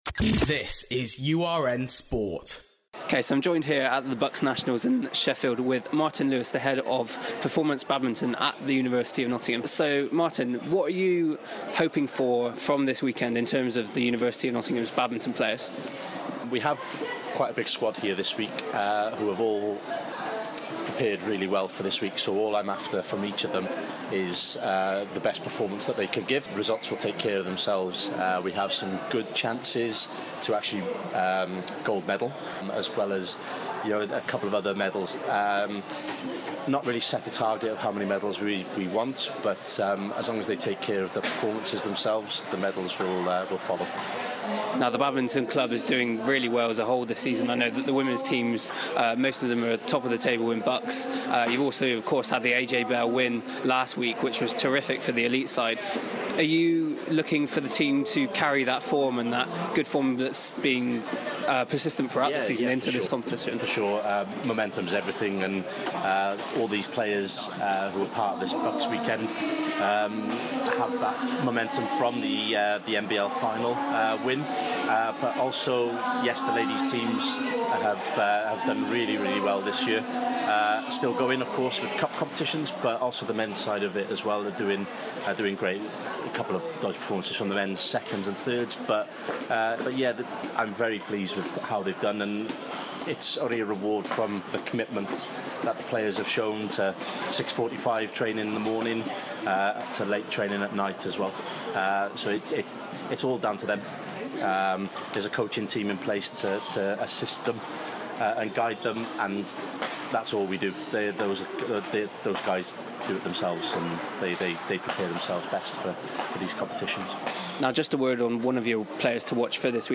BUCS Nationals Interviews - UoN Badminton